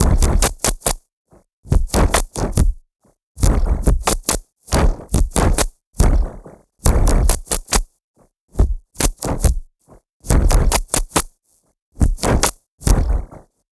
Abstract Rhythm 17.wav